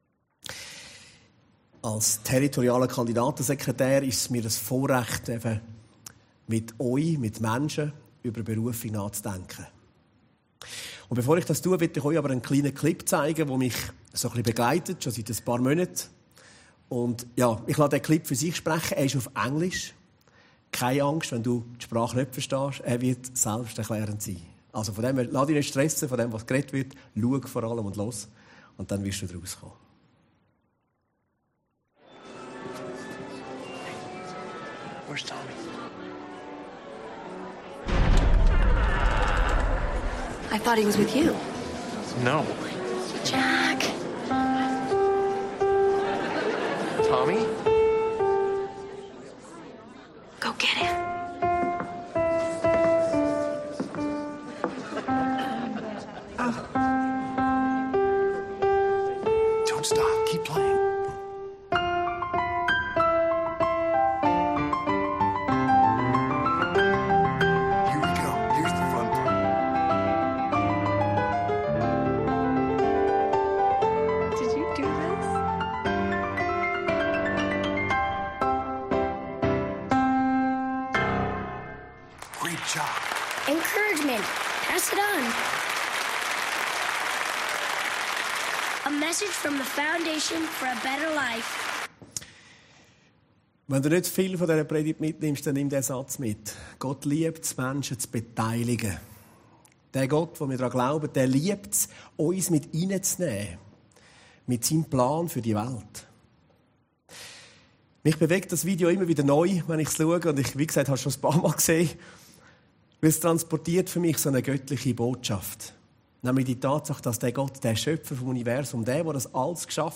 Predigten Heilsarmee Aargau Süd – Herzschlag-Gottes Leidenschaft, Menschen zu beteiligen